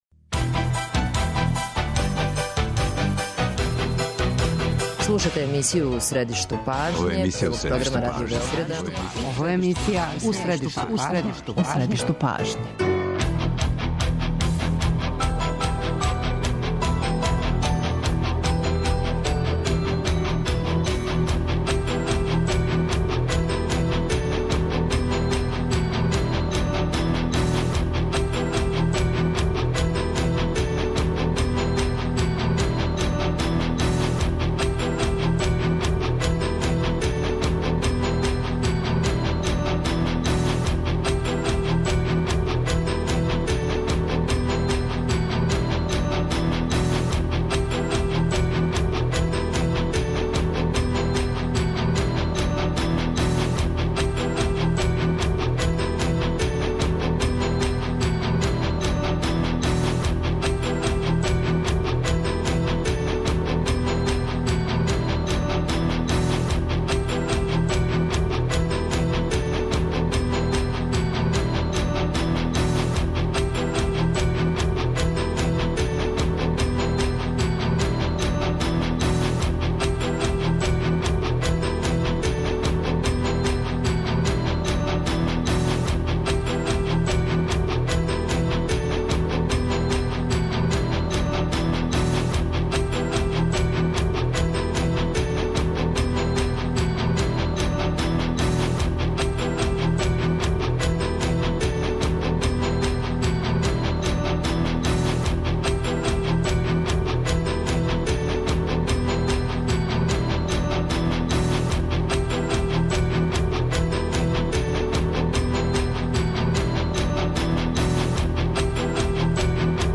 Имају ли странке одговоре на питања која интересују младе? Зашто само 10% младих до 25 година излази на биралишта? Гости емисије су представници странака, укључујући лидере страначких омладина, са којима ћемо разговарати о томе шта нуде младима и како намеравају да их мотивишу да буду политички активнији.